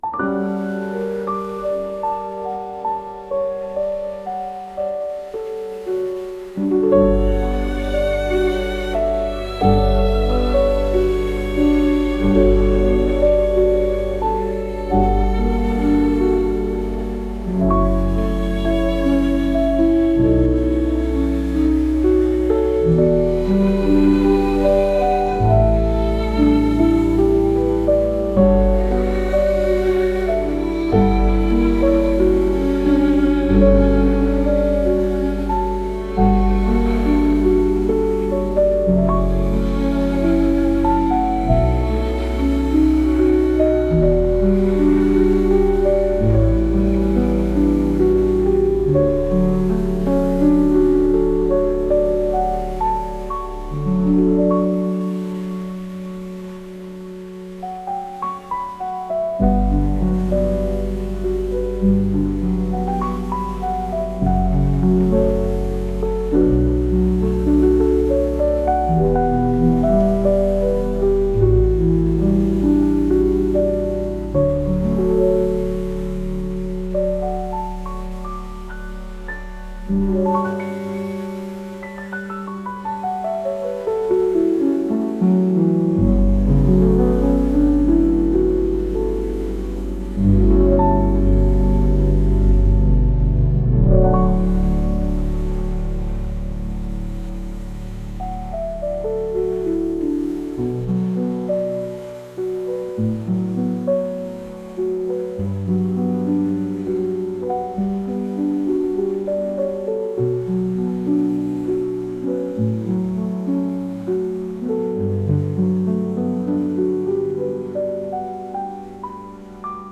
夜が明けて朝になろうかという雰囲気のピアノとバイオリン曲です。